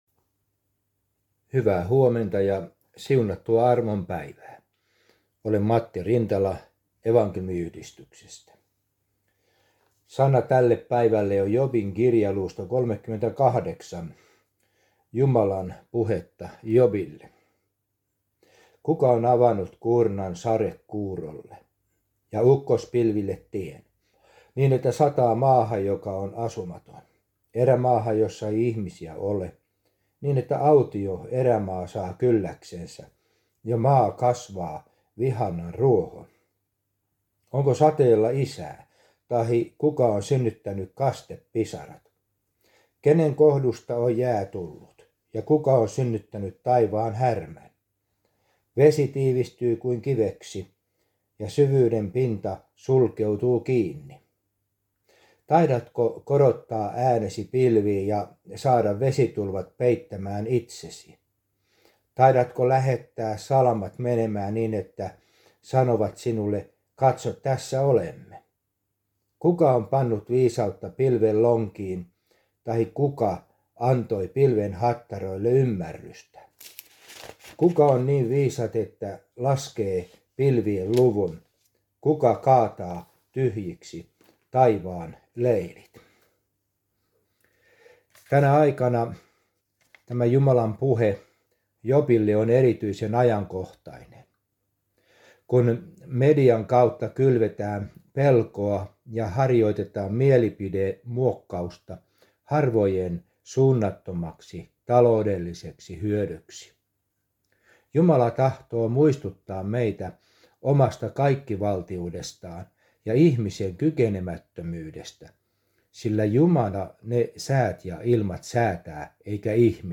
Aamuhartaus Järviradioon 26.7.2023
aamuhartaus_jarviradioon__0.mp3